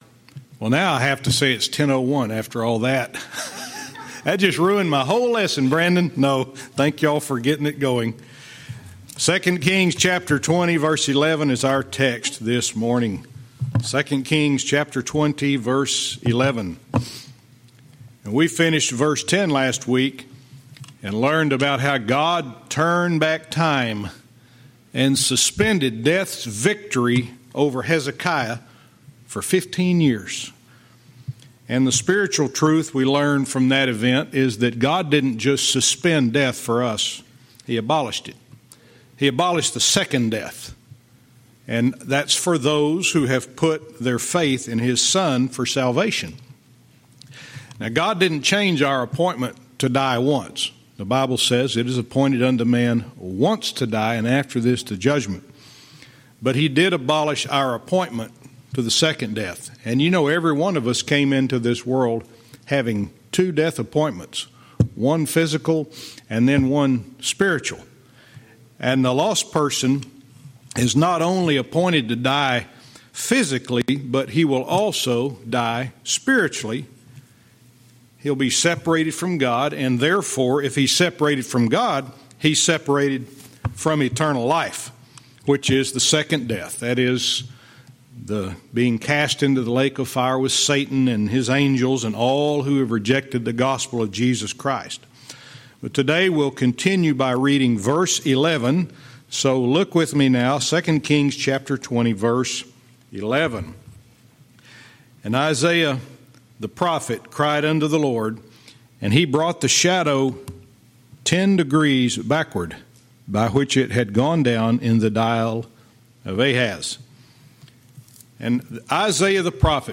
Verse by verse teaching - 2 Kings 20:11-13